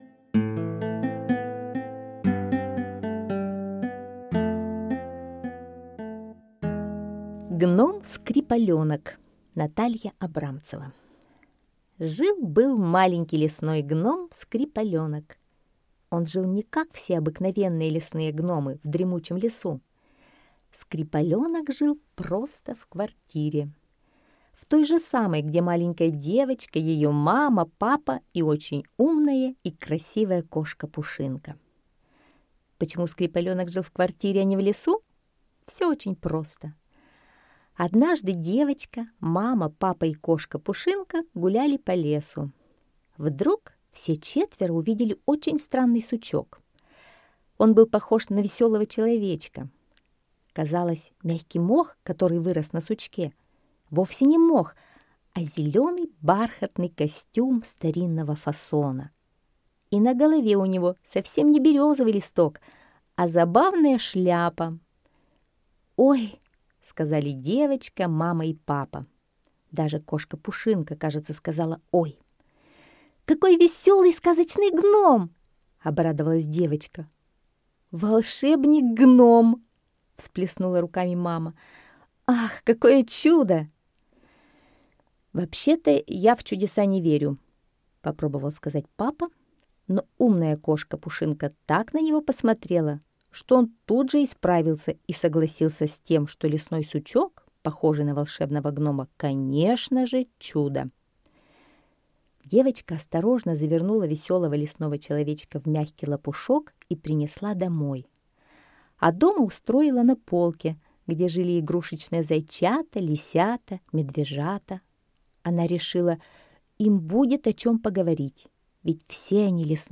Аудиосказка «Гном Скрипаленок»